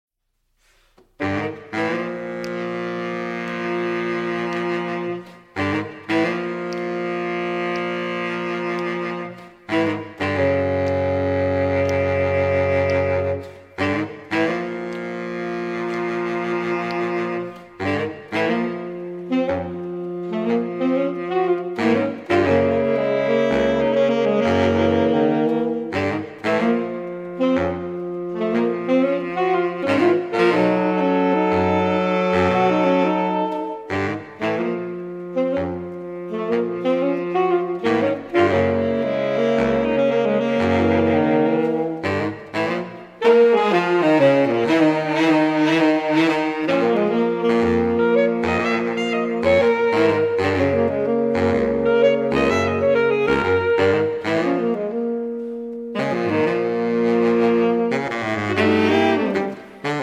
live recordings
baritone saxophone
tenor saxophone
alto saxophone
soprano and alto saxophone